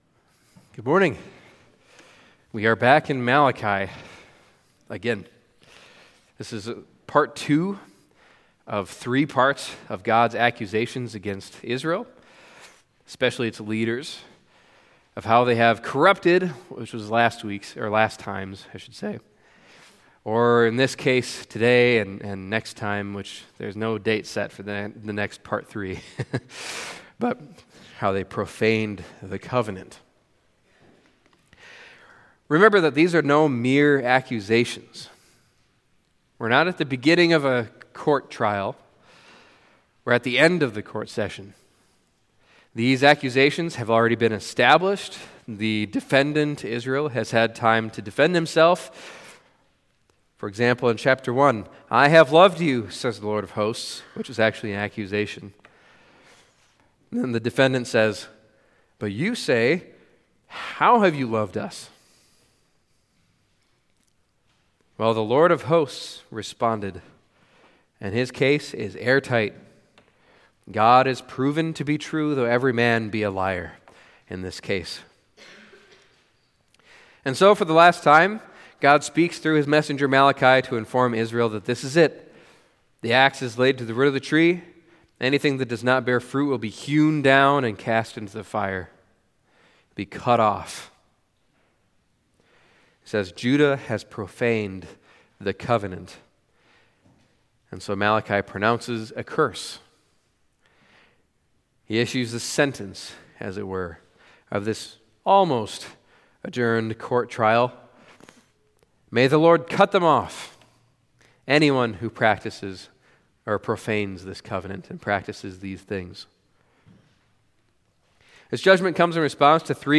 Malachi Passage: Malachi 2:10-12 Service Type: Sunday Morning Malachi 2:10-12 « Condemned or Accepted?